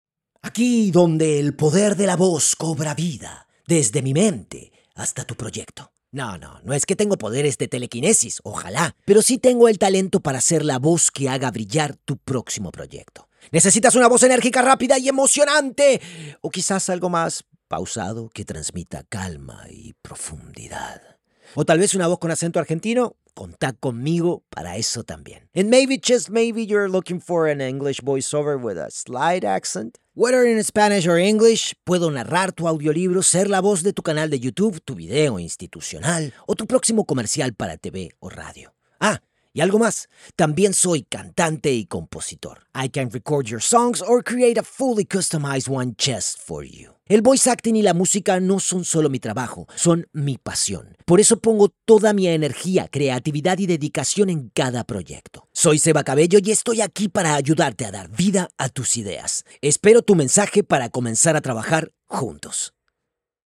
Espagnol (argentin)
Démo commerciale
Ma voix est naturellement chaleureuse, expressive et polyvalente, ce qui la rend idéale pour les projets qui nécessitent un ton authentique et crédible ou une performance plus dynamique et énergique.